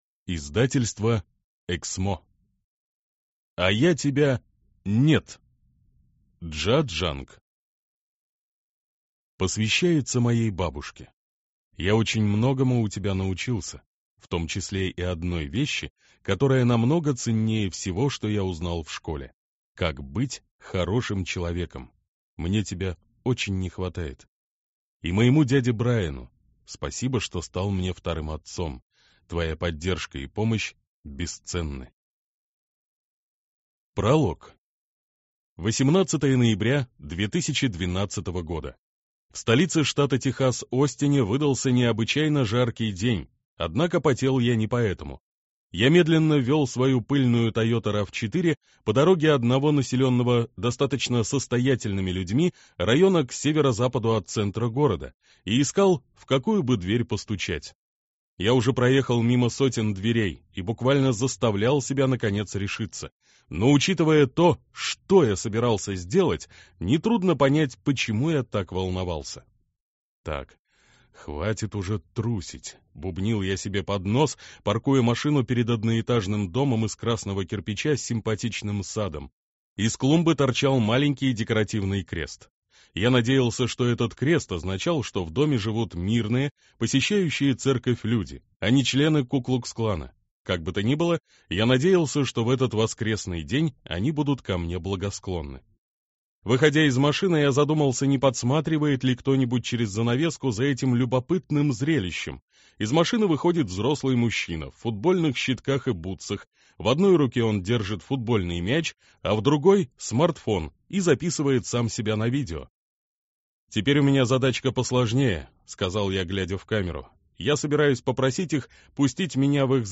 Аудиокнига А я тебя «нет». Как не бояться отказов и идти напролом к своей цели | Библиотека аудиокниг